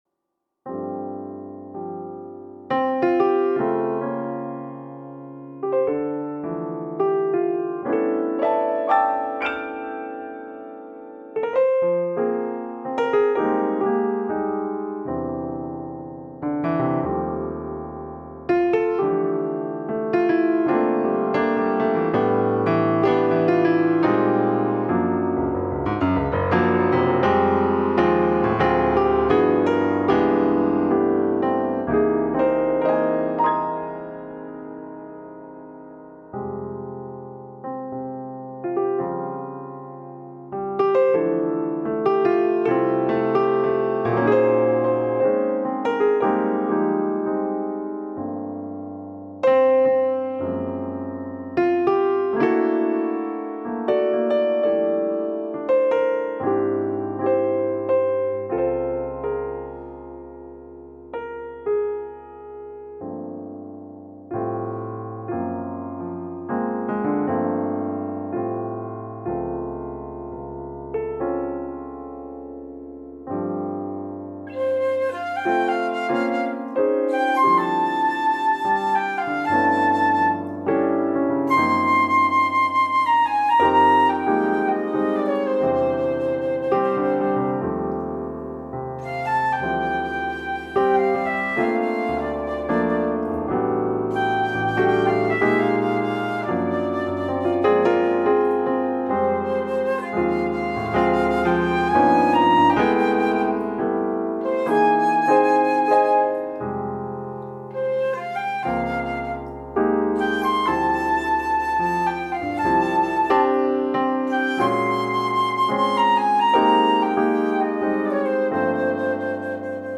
” a waltz